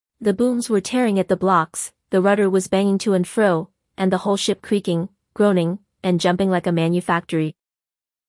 hsiaochen(edgetts).mp3